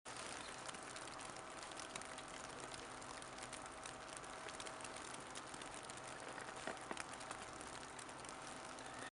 Download Snow sound effect for free.
Snow